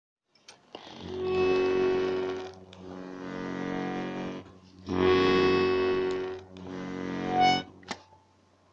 Звон в басах
У меня гармонь Рябинушка.